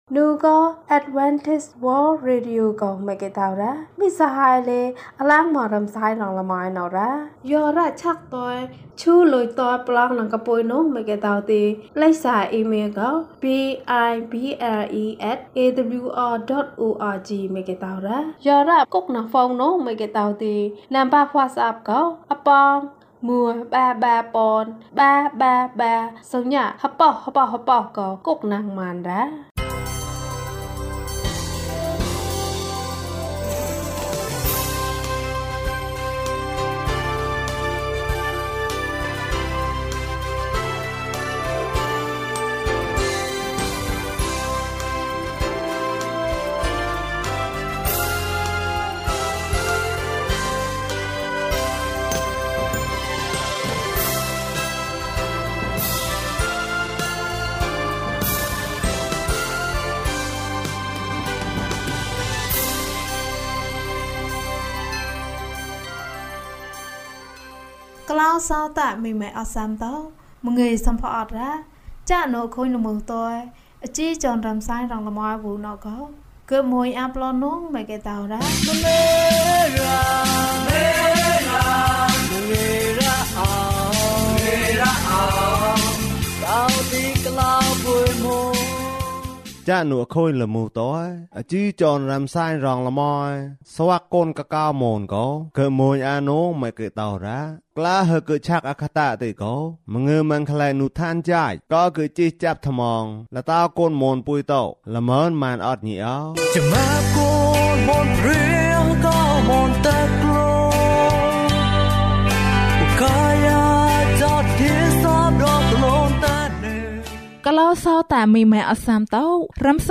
ယေရှုသည် ကျွန်ုပ်၏ကယ်တင်ရှင်ဖြစ်သည်။၀၂ ကျန်းမာခြင်းအကြောင်းအရာ။ ဓမ္မသီချင်း။ တရားဒေသနာ။